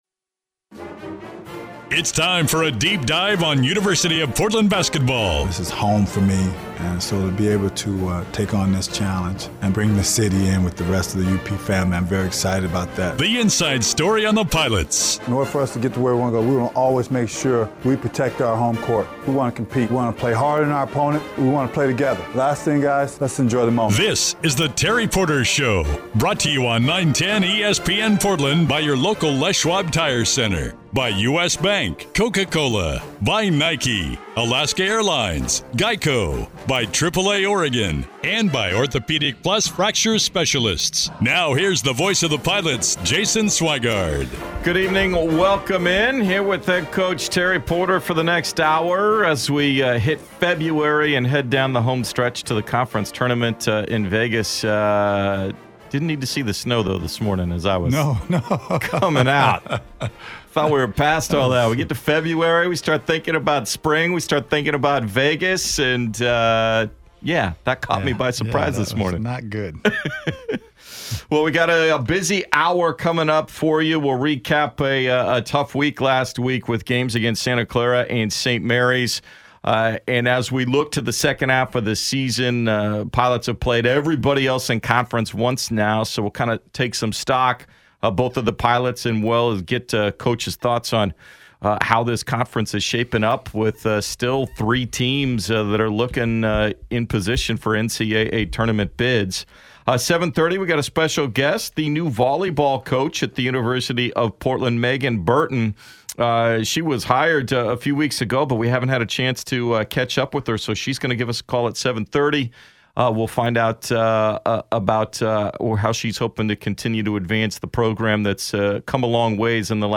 in-studio to chat UP hoops